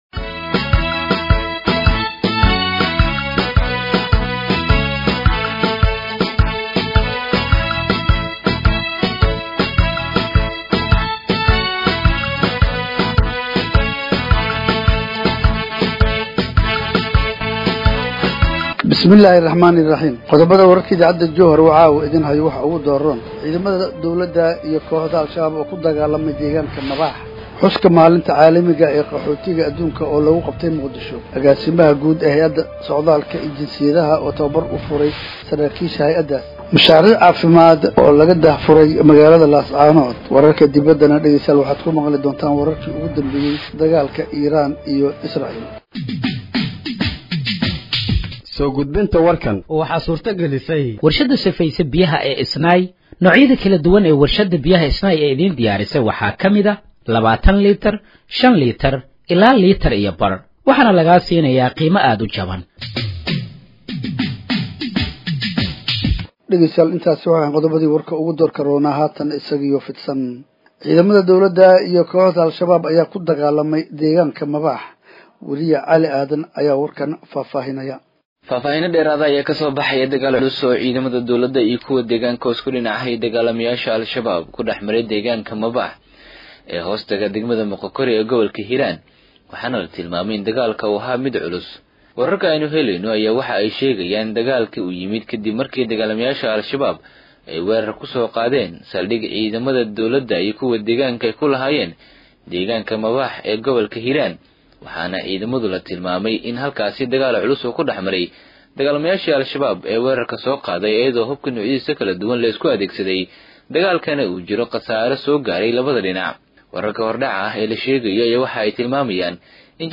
Dhageeyso Warka Habeenimo ee Radiojowhar 22/06/2025